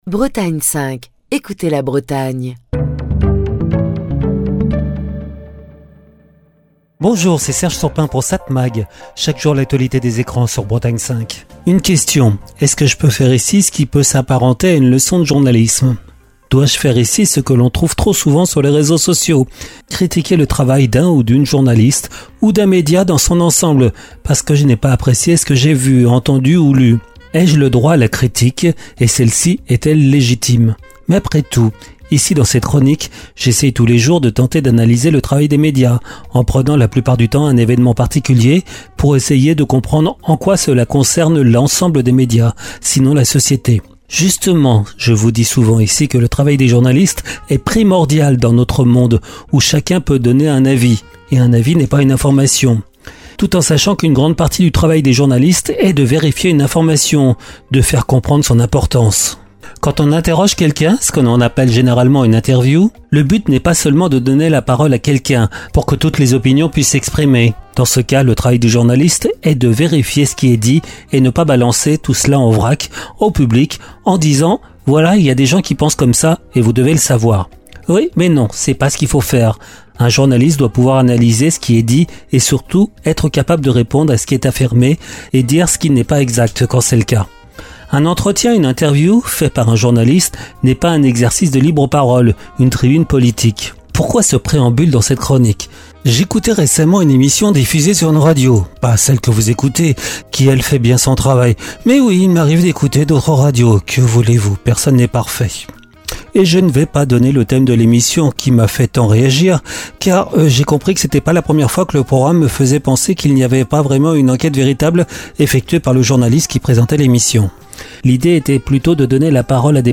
Chronique du 8 avril 2025.